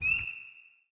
frog3.wav